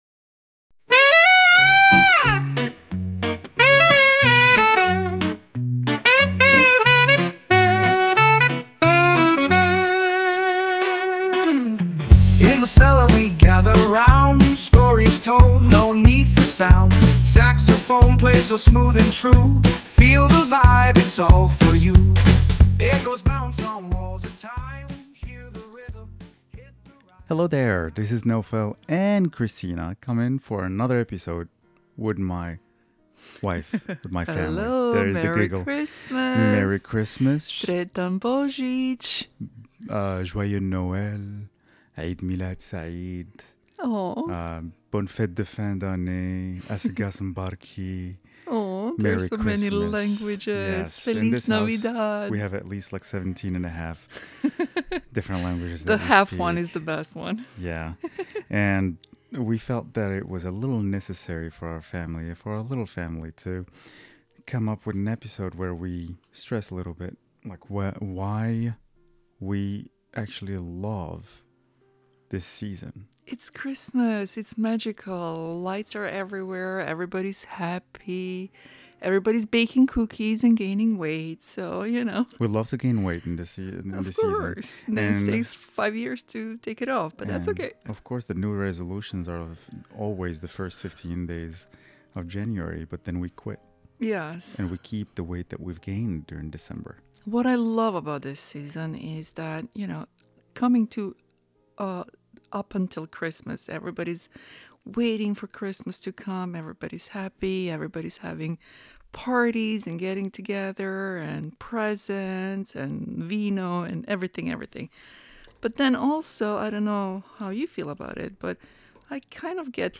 From cherished traditions to the beauty of celebrating different cultures, we explore the ways this time of year brings people together. Join us for a heartfelt conversation filled with love, laughter, and a touch of holiday spirit as we share our thoughts on what truly makes this season special.